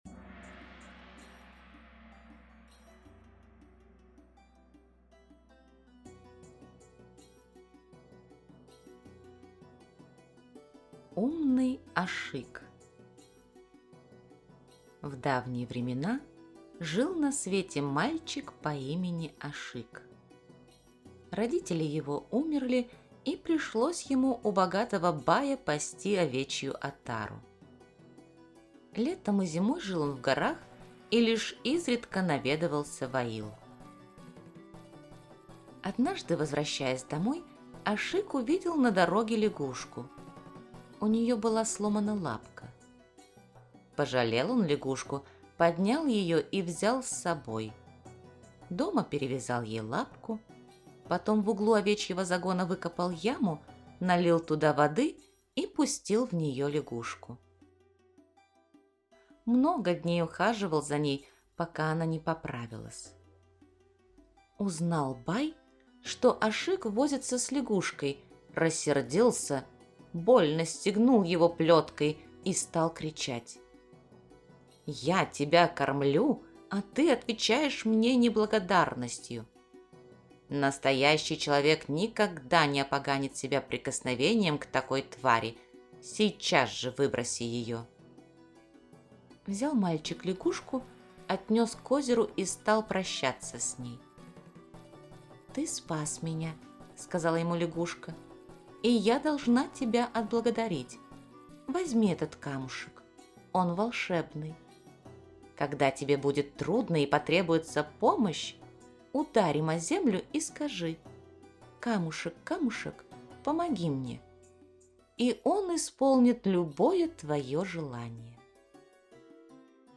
Умный Ашик - киргизская аудиосказка - слушать онлайн